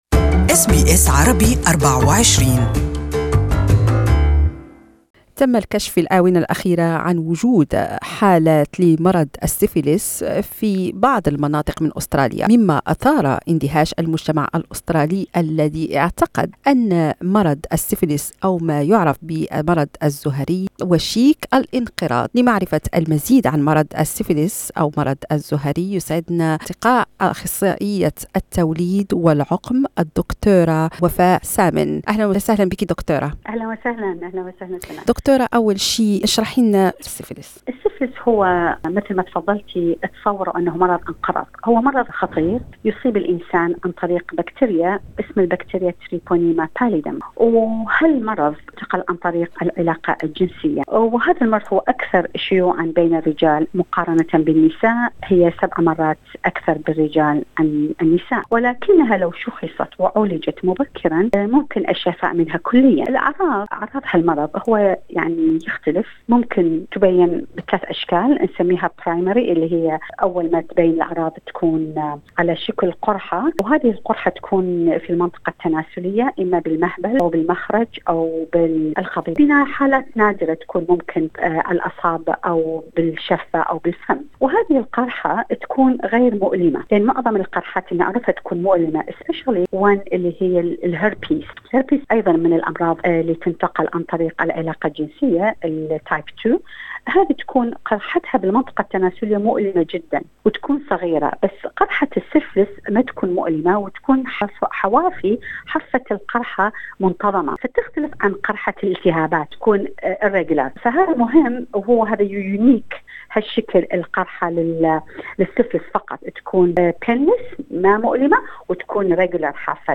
استمعوا الى هذا اللقاء في المقطع الصوتي أعلاه.